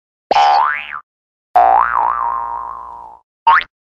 Cartoon Boing Spring Sound Button - Free Download & Play